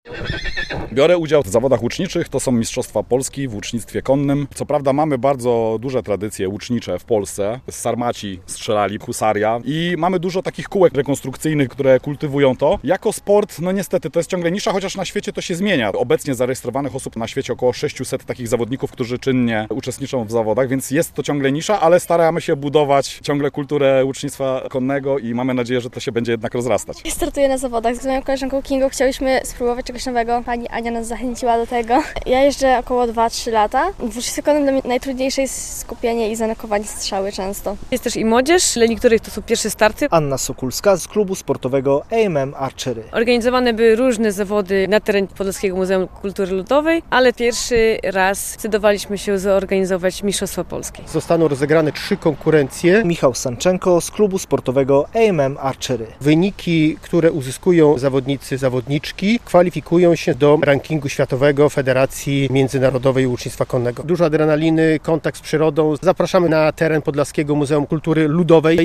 Mistrzostwa Polski w łucznictwie konnym - relacja